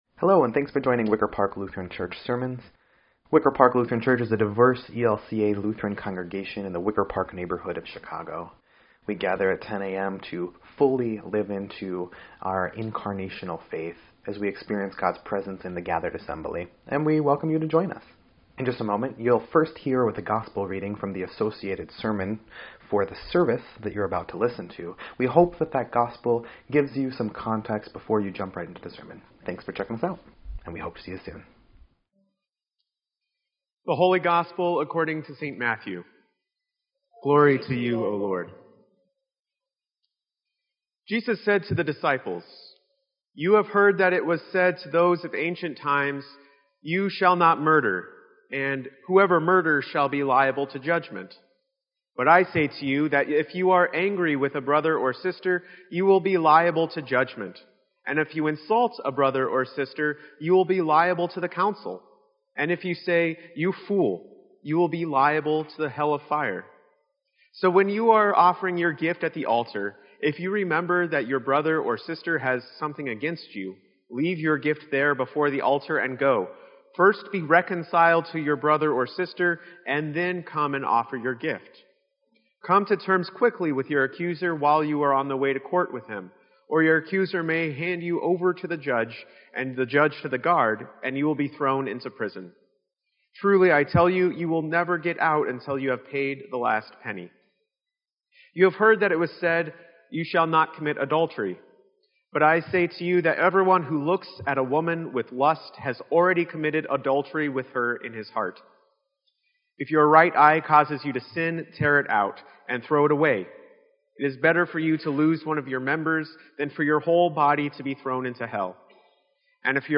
Sermon_2_12_17_EDIT.mp3